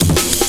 amen chop.wav